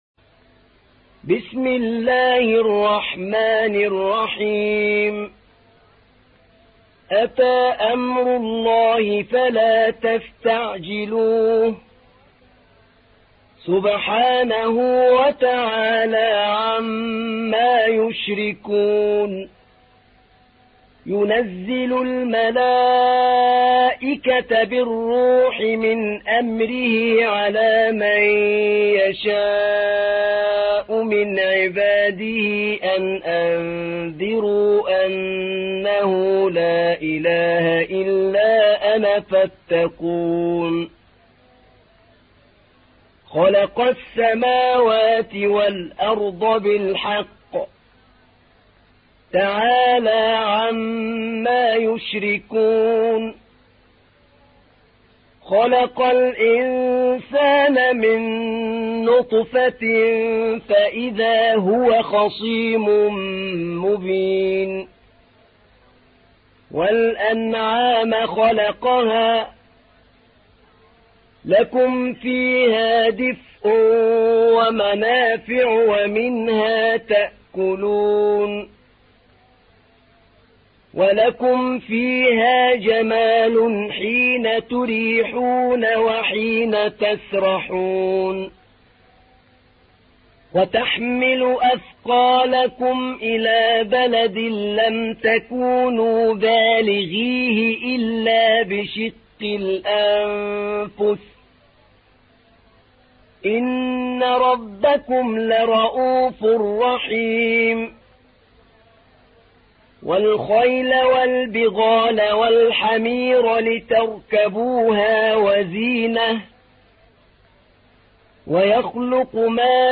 تحميل : 16. سورة النحل / القارئ أحمد نعينع / القرآن الكريم / موقع يا حسين